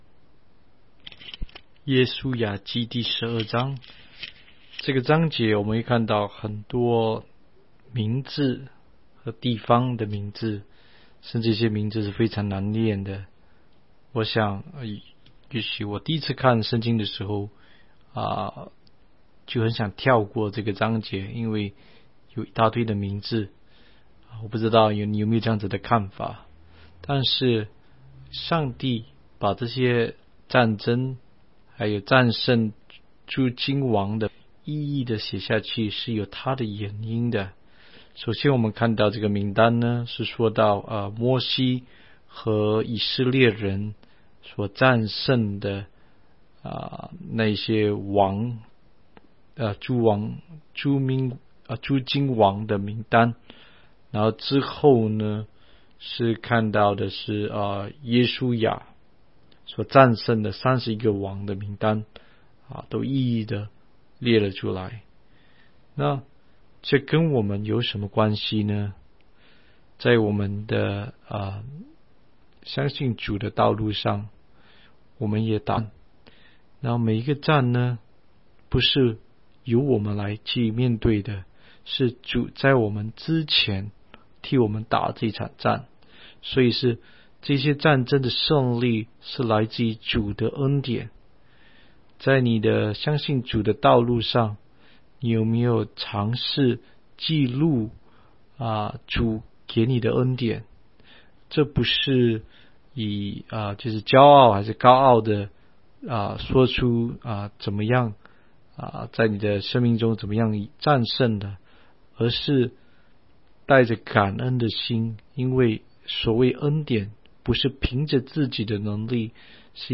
16街讲道录音 - 每日读经-《约书亚记》12章